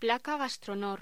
Locución: Placa gastronor
voz